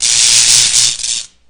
DoorSparks.ogg